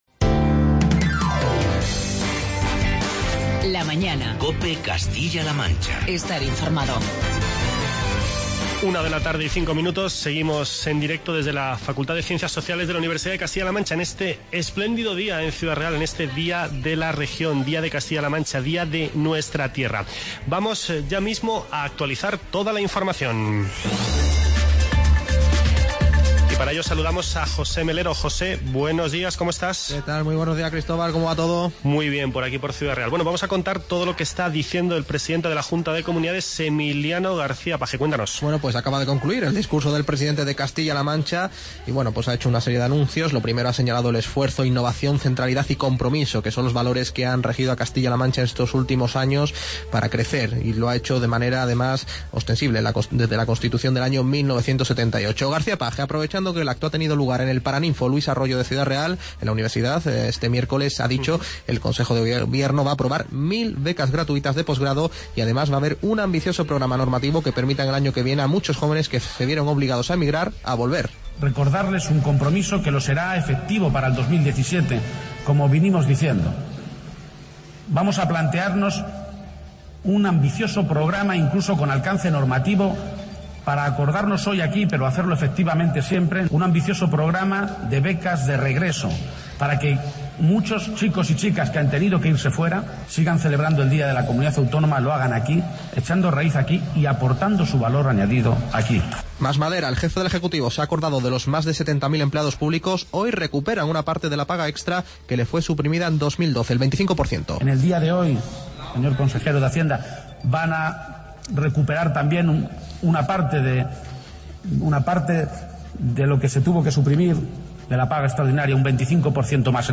Seguimos desde Ciudad Real celebrando el Día de la Región. Por los micrófonos de COPE muchos invitados, entre ellos, la consejero de Empleo, el de Sanidad o el portavoz del Gobierno regional.